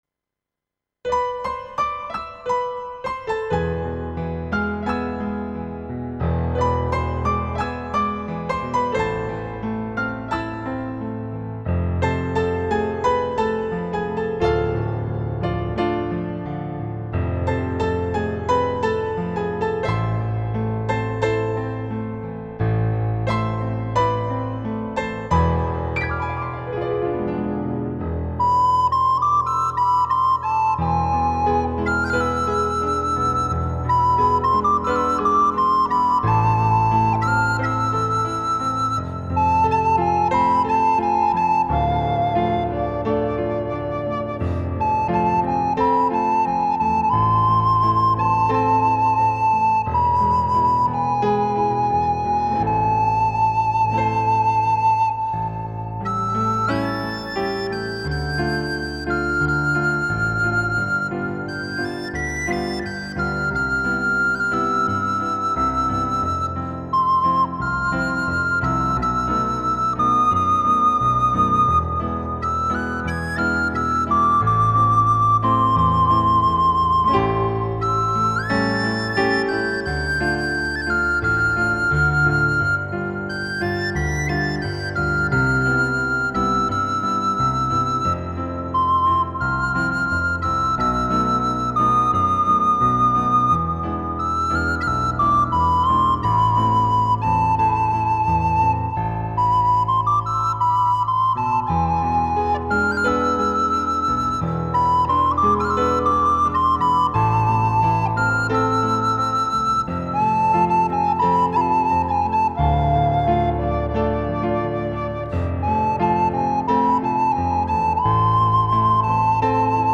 • با رعایت جزئیات ریتم و ملودی اصلی
🎧 فایل صوتی MP3 اجرای مرجع
• اجرای دقیق با تمپو و دینامیک استاندارد